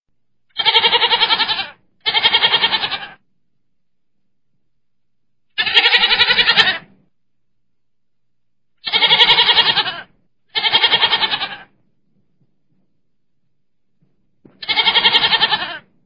دانلود آهنگ بز از افکت صوتی انسان و موجودات زنده
جلوه های صوتی
دانلود صدای بز از ساعد نیوز با لینک مستقیم و کیفیت بالا